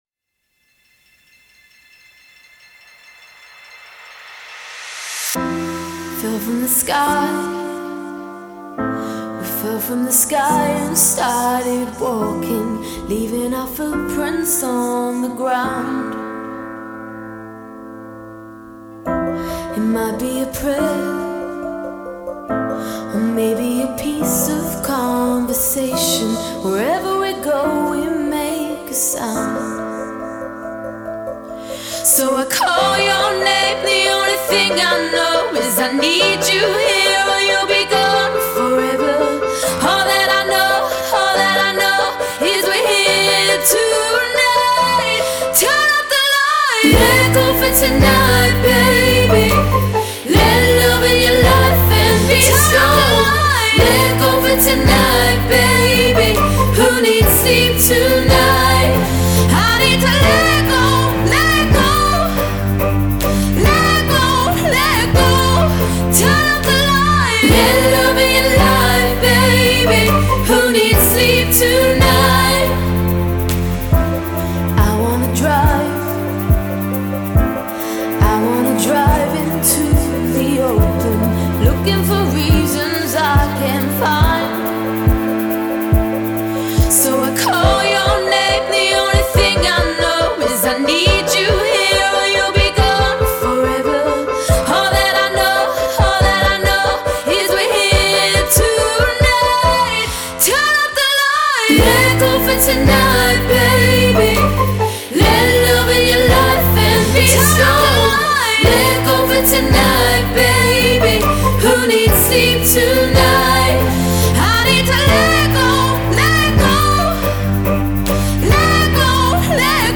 (demo version)